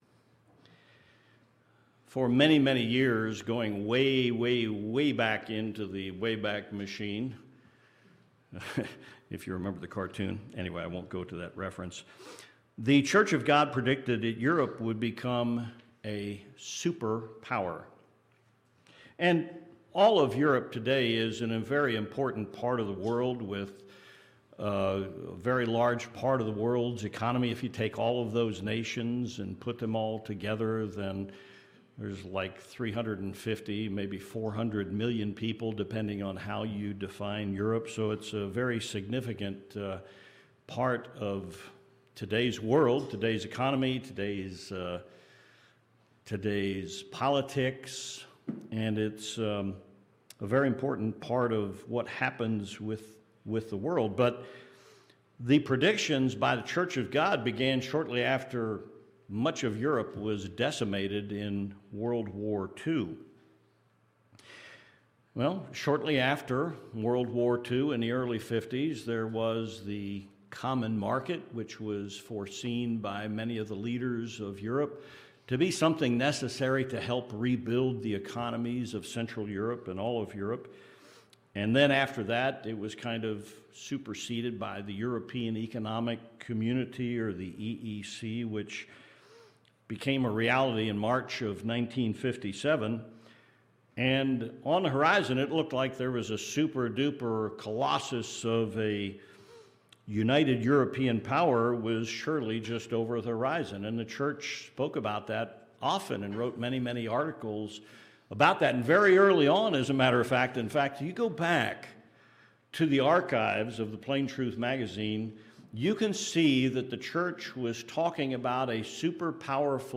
Sermons
Given in El Paso, TX